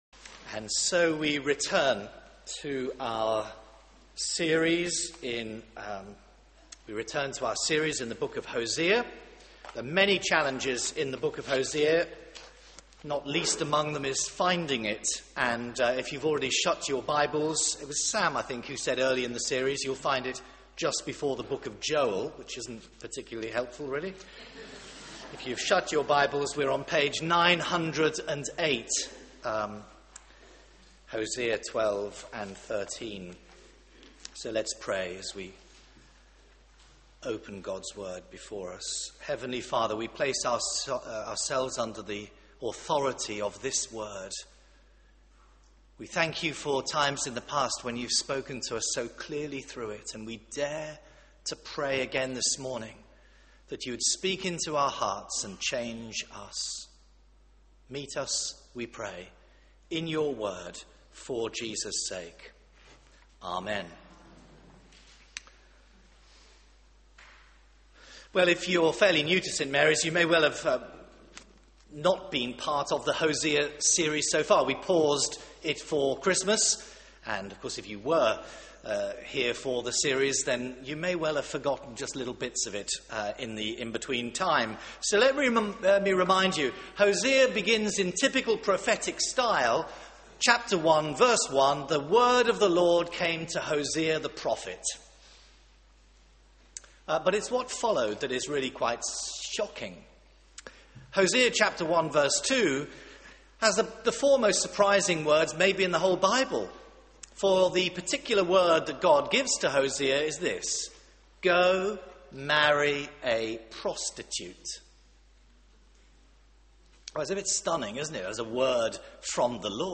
Media for 9:15am Service on Sun 15th Jan 2012 09:15 Speaker
Series: Hosea: A love story Theme: A jealous God Sermon